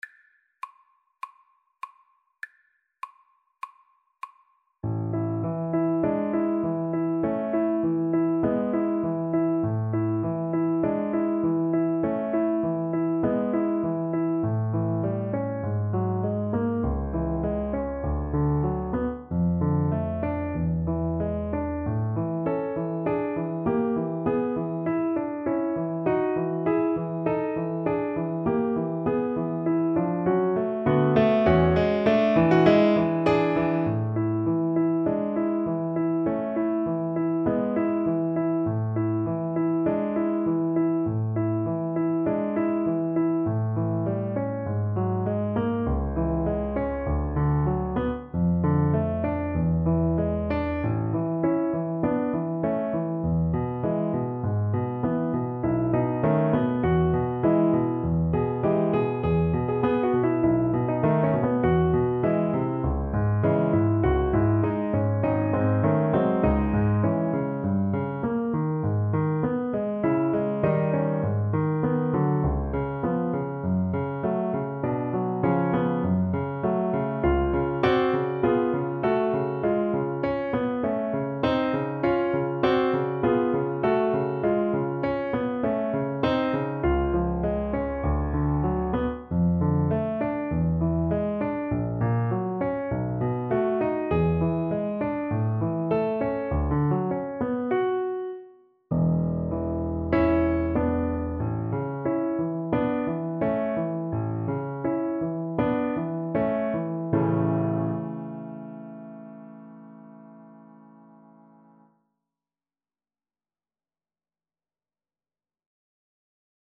4/4 (View more 4/4 Music)
A major (Sounding Pitch) (View more A major Music for Violin )
Classical (View more Classical Violin Music)